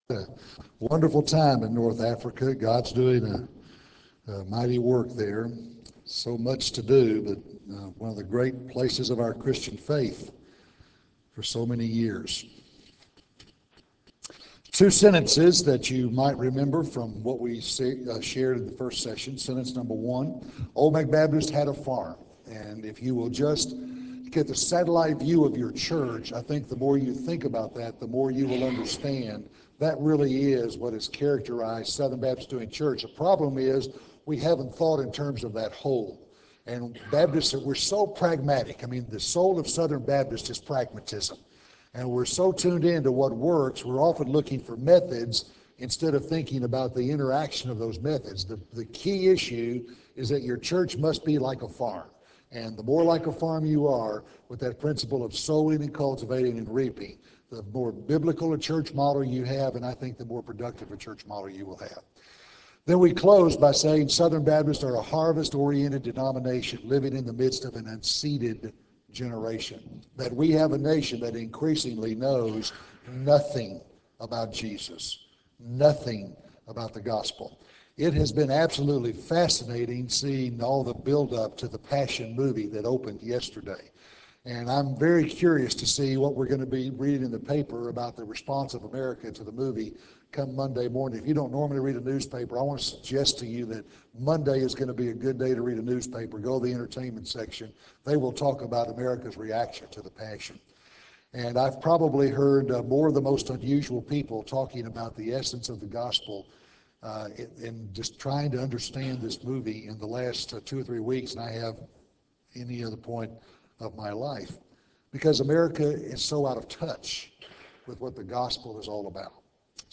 Address: "The Big Goal"